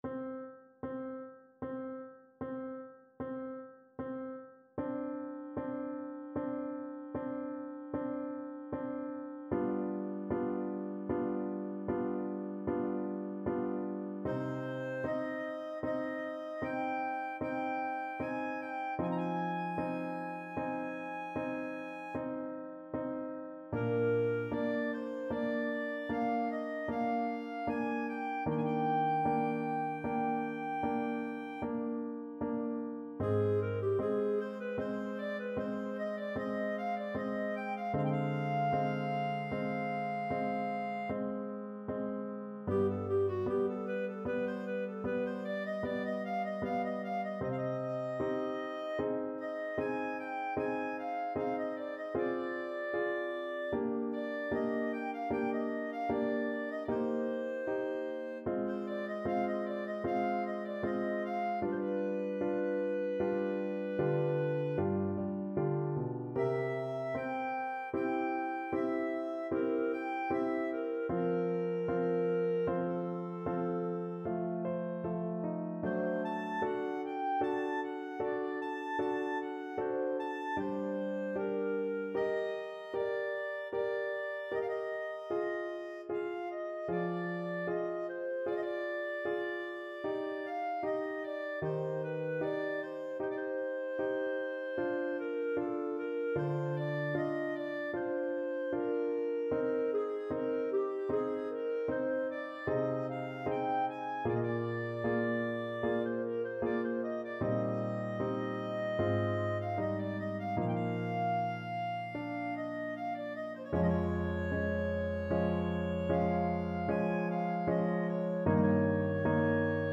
Clarinet
C minor (Sounding Pitch) D minor (Clarinet in Bb) (View more C minor Music for Clarinet )
Adagio =38 Adagio
3/4 (View more 3/4 Music)
Classical (View more Classical Clarinet Music)